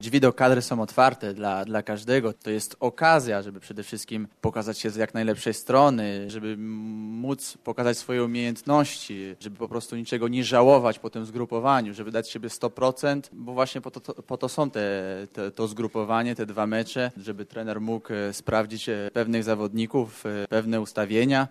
Jak walczyć o skład na zgrupowaniu mówi Grzegorz Krychowiak z Sevilli.